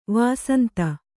♪ vāsanta